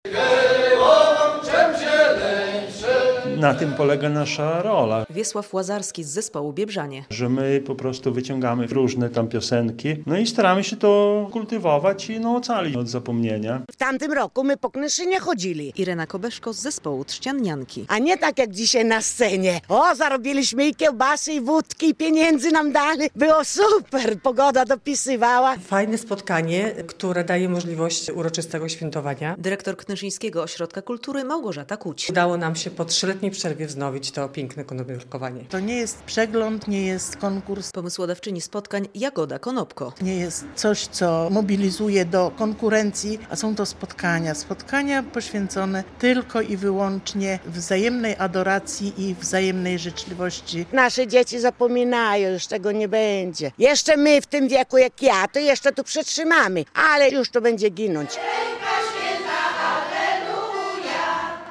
Blisko 20 zespołów folklorystycznych i kompanii męskich z Polski i Litwy wyśpiewało wiosenne tradycyjne życzenia podczas 19. Międzynarodowych Wiosennych Spotkań z Konopielką w Knyszynie, które w niedzielę (16.04) odbyły się w miejscowym ośrodku kultury.
relacja